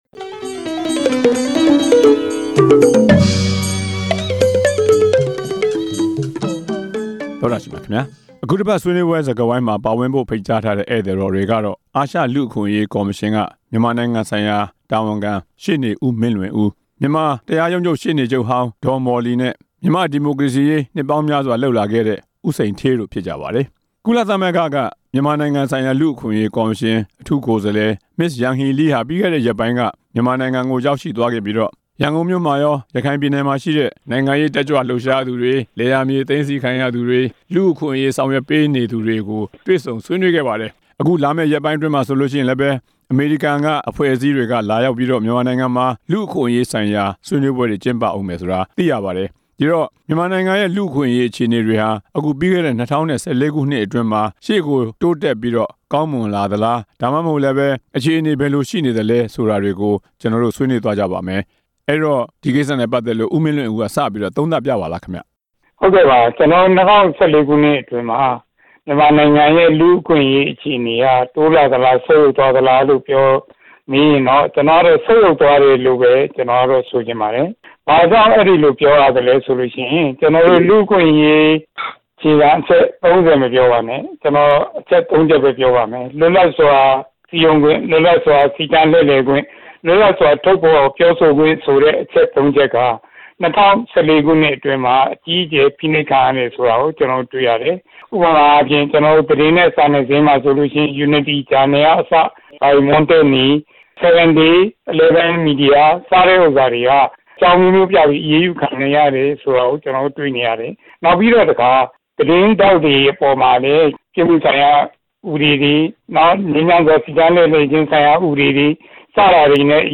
မြန်မာနိုင်ငံ လူ့အခွင့်အရေး အခြေအနေ ဆွေးနွေးချက်